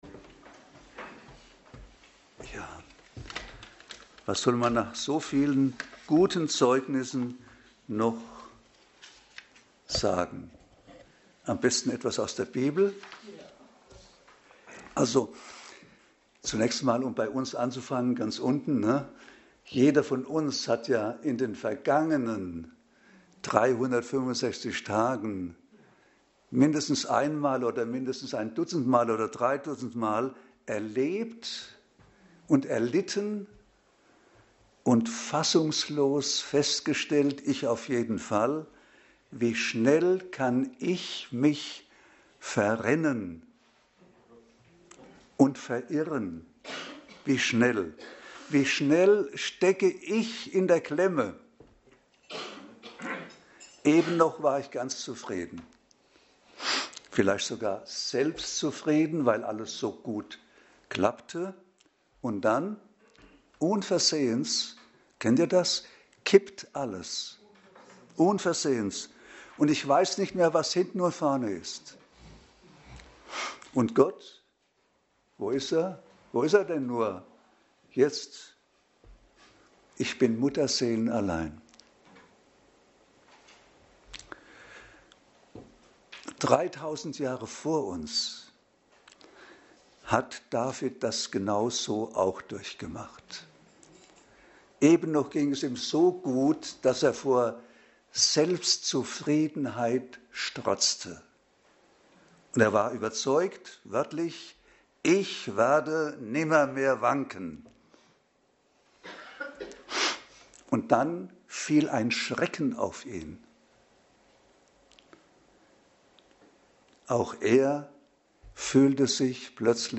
Passage: Psalm 30 Dienstart: Predigt